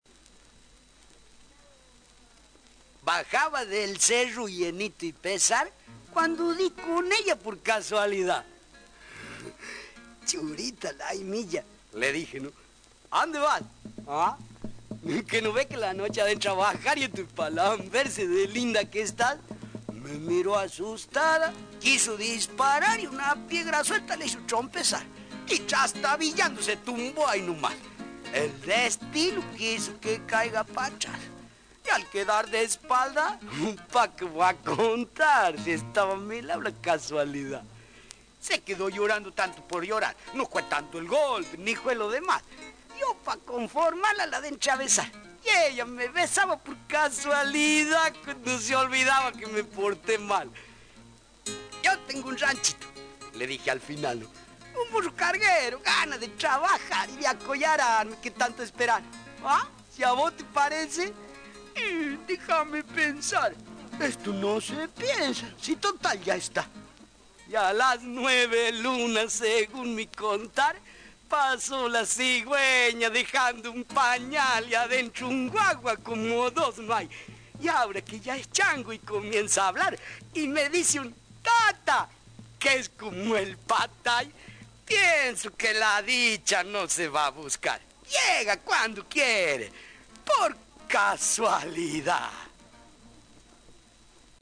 En homenaje al personaje que creó en un inicio, dicen que  allí en Jujuy  con ese acento coya, dejamos un recitado,  pero del bonaerense Boris Elkin que supo también invocar la esencia del hombre norteño en algunos de sus poemas: “Por casualida” publicado en su libro “Charqueando”.